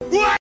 嫌弃.mp3